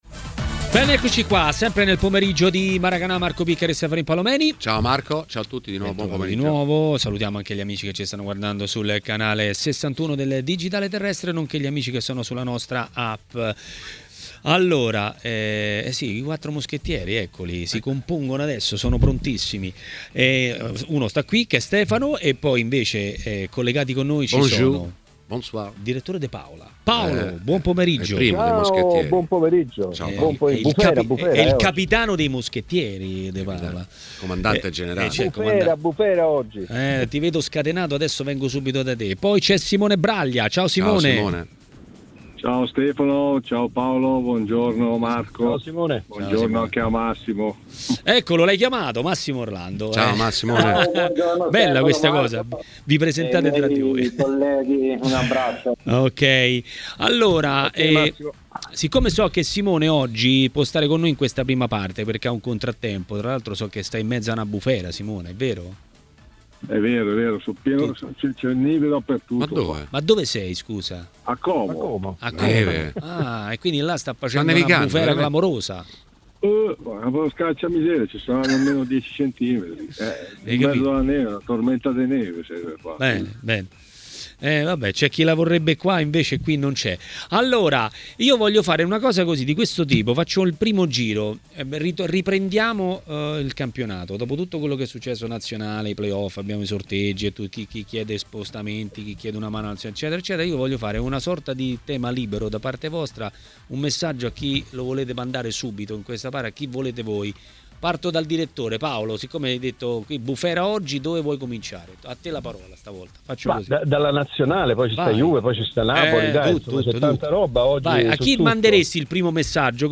Le Interviste
L'ex calciatore Simone Braglia è stato ospite di Maracanà, trasmissione di TMW Radio.